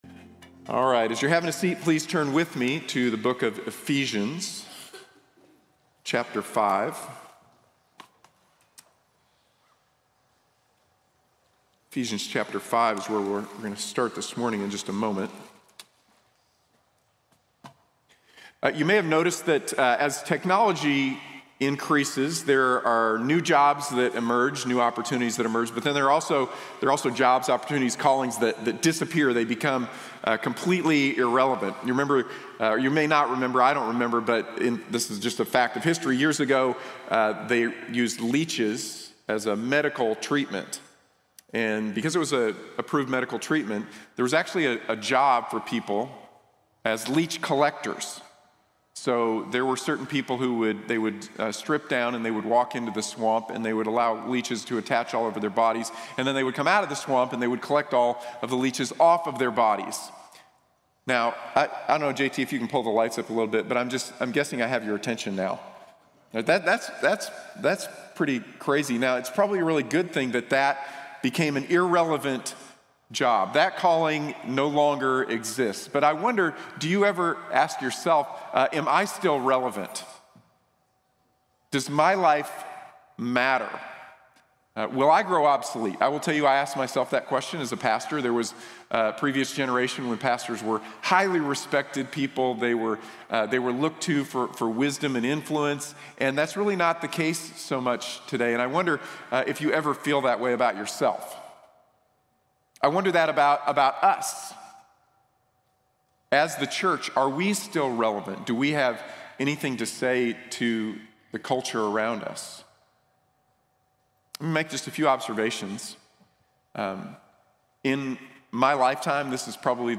Somos la Iglesia | Sermón | Iglesia Bíblica de la Gracia